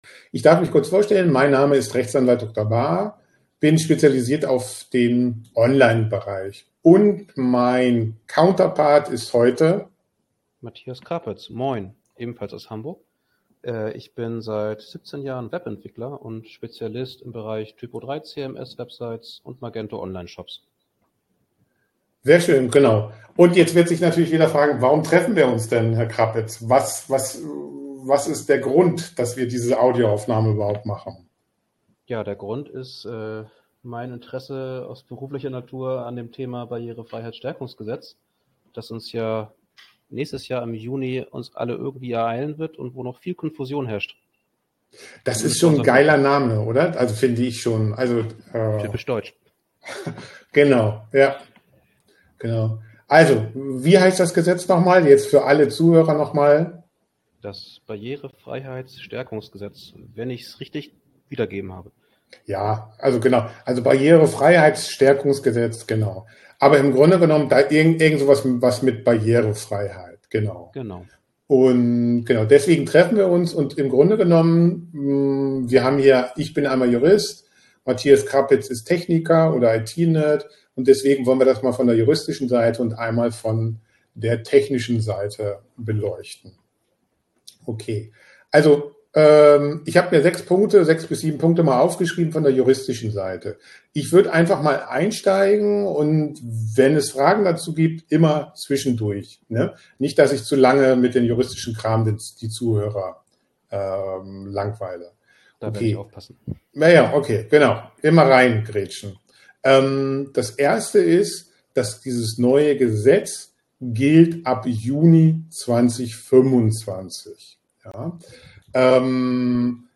We met online for a casual conversation between lawyer and web developer to find some answers to these and other questions.